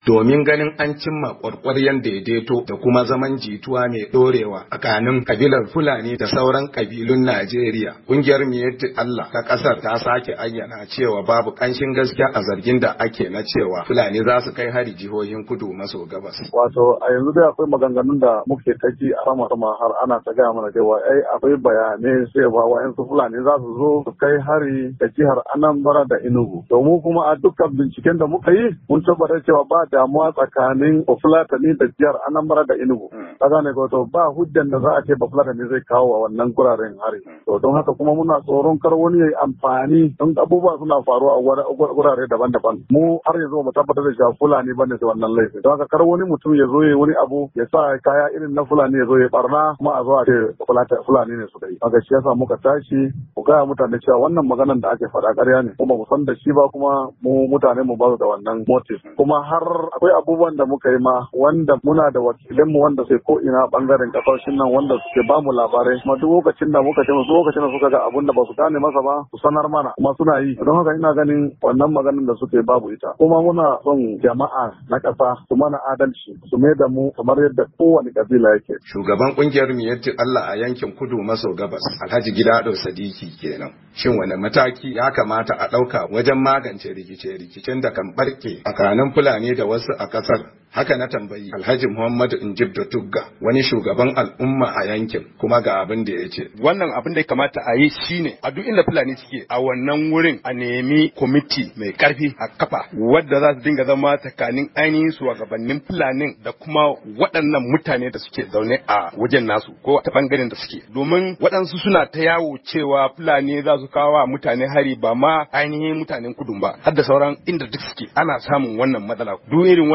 rahoton